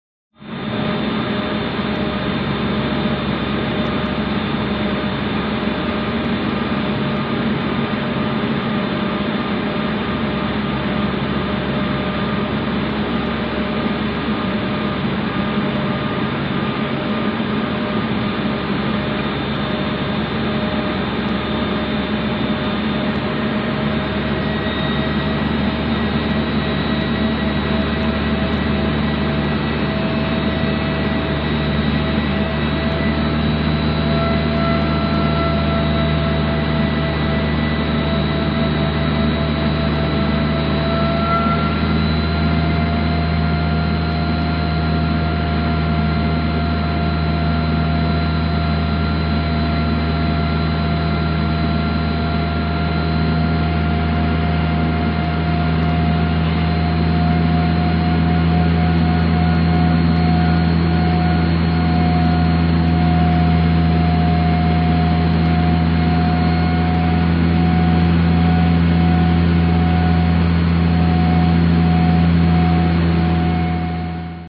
AIRCRAFT PROP TWIN TURBO: INT: Steady flight, ascending, increasing speed.